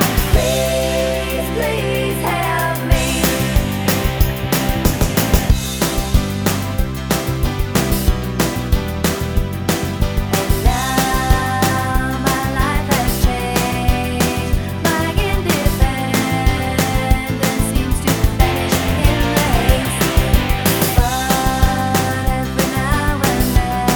Pop (1980s) 2:41 Buy £1.50